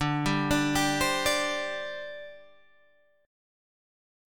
Listen to D7sus4 strummed